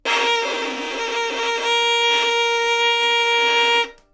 小提琴单音（弹得不好） " 小提琴 Asharp4 坏的丰富性
描述：在巴塞罗那Universitat Pompeu Fabra音乐技术集团的goodsounds.org项目的背景下录制。单音乐器声音的Goodsound数据集。
Tag: 好声音 单注 小提琴 多样本 Asharp4 纽曼-U87